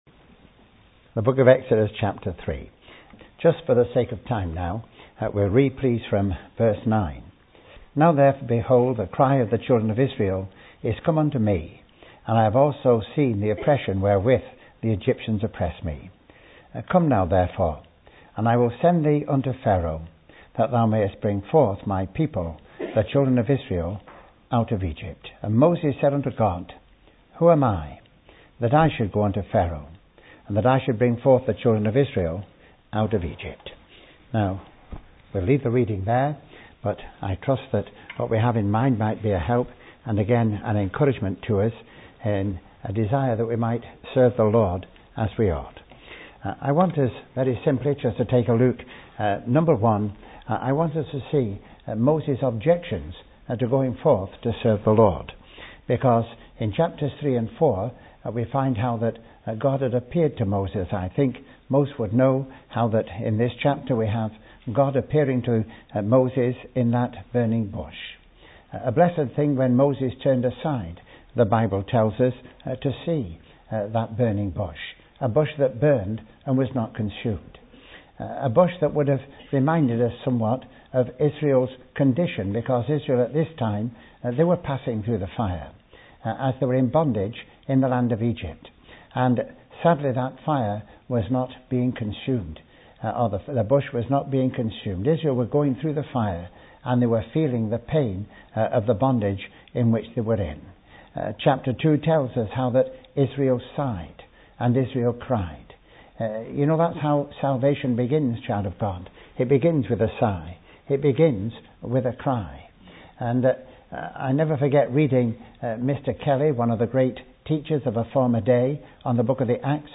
preaches a stirring and powerful message on the challenge of Christian service, separation and consecration